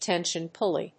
tension+pulley.mp3